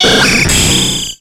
Cri d'Airmure dans Pokémon X et Y.